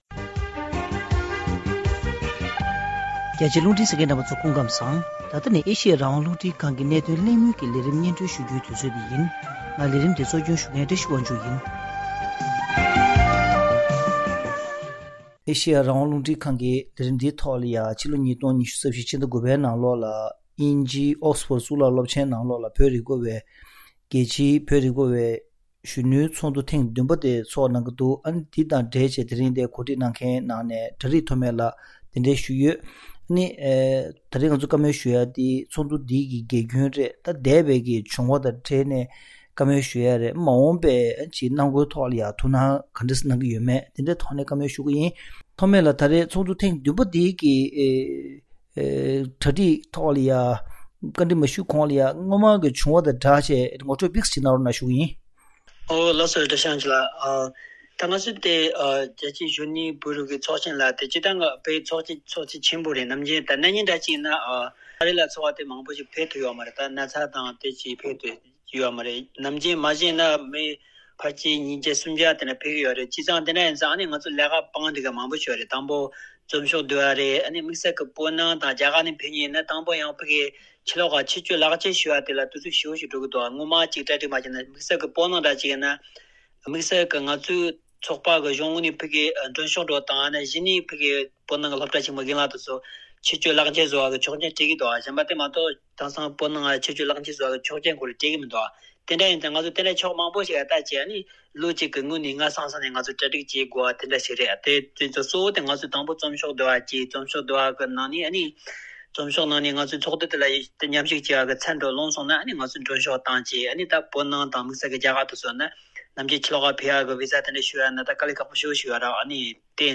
གླེང་མོལ་ཞུས་པའི་ལས་རིམ།